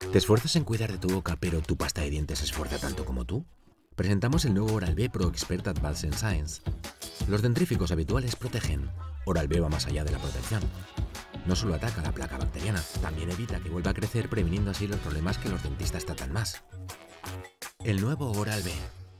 Voz neutral, emotiva, energética y divertida
Profesional Studio at home